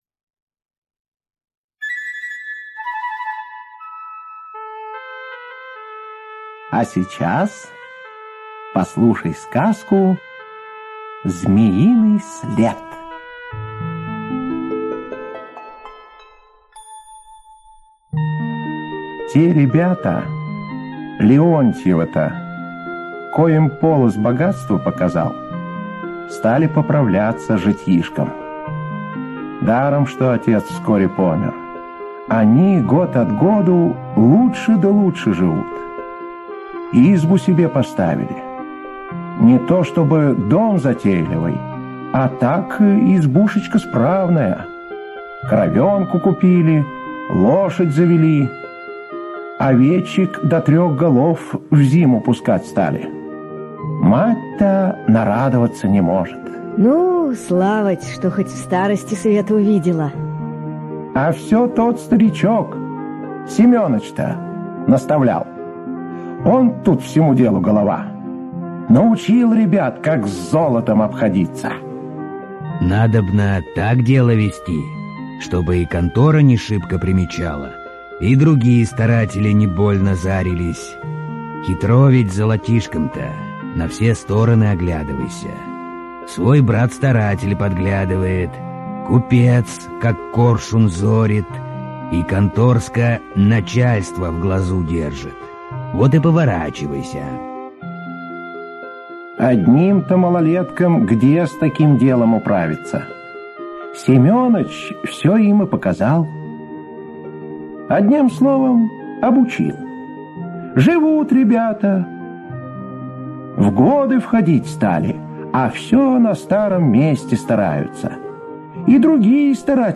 Слушайте Змеиный след - аудиосказка Бажова П. Сказ про двух братьев - Левонтовых сыновей, которым Великий Полоз богатство показал.